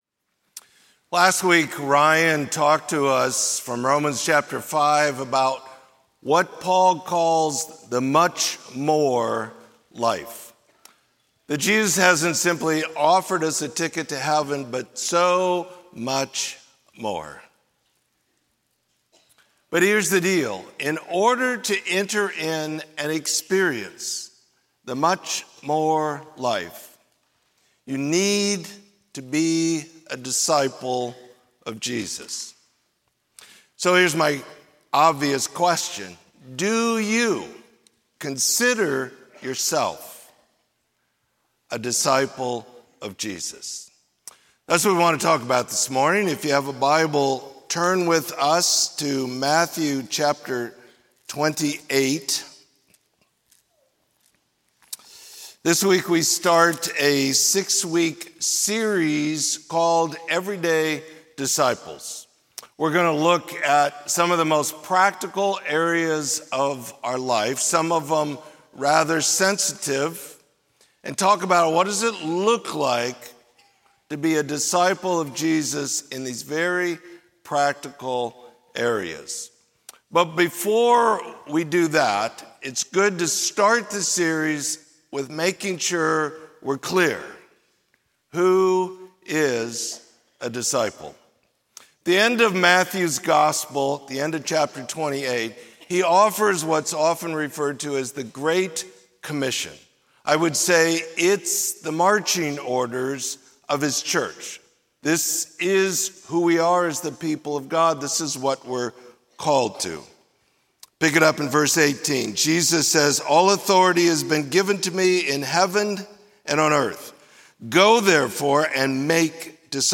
Sermon: Who is a Disciple?